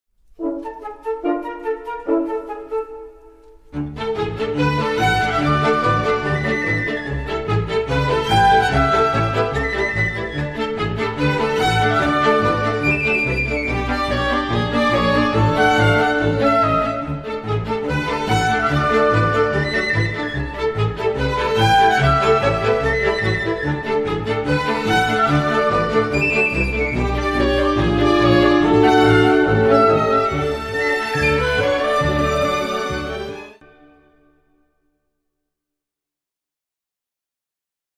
The CD contains both vocal and orchestral pieces.
* World première modern recordings